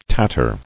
[tat·ter]